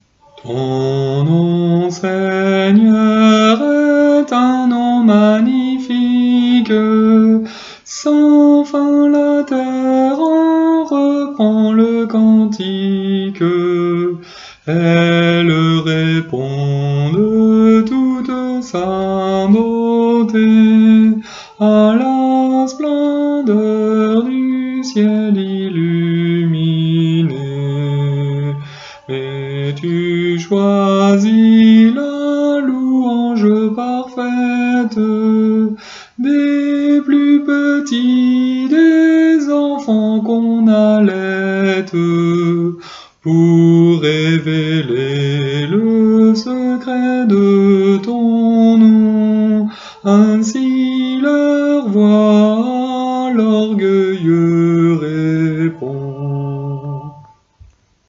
Psautier huguenot, de Genève, français, avec partition, parole et mélodie.
Mode de Ré authente.
Mélodie